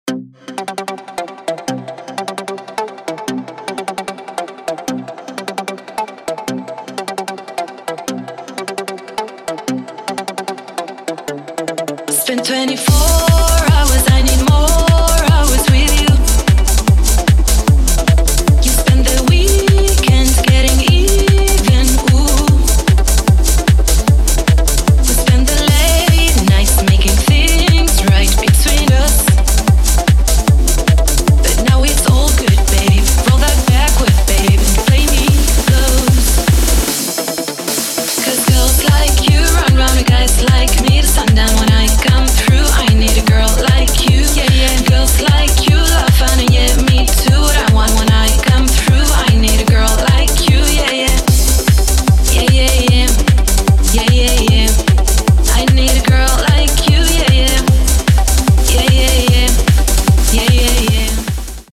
• Качество: 256, Stereo
громкие
женский вокал
dance
Electronic
электронная музыка
Hard dance